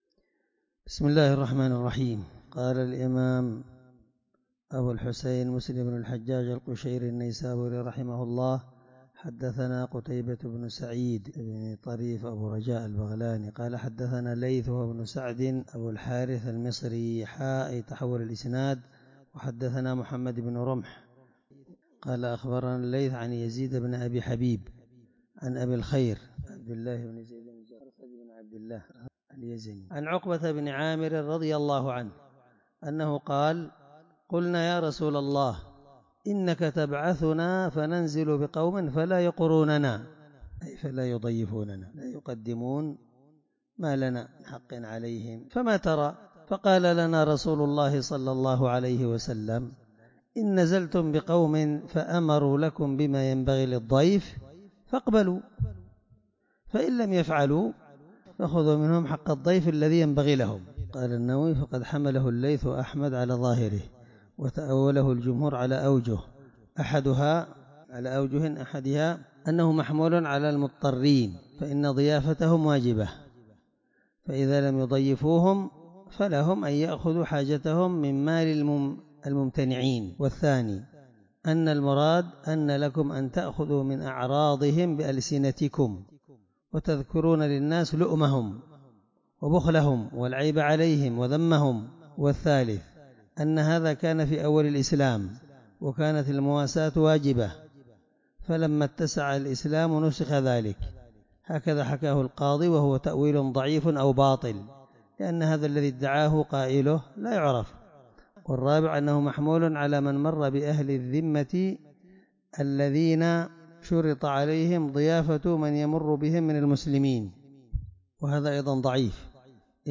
الدرس6من شرح كتاب اللقطة الحدود حديث رقم(1727) من صحيح مسلم